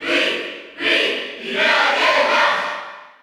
Category: Crowd cheers (SSBU) You cannot overwrite this file.
Pit_Cheer_Spanish_PAL_SSBU.ogg